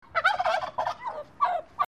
Pavo doméstico (Meleagris gallopavo domesticus)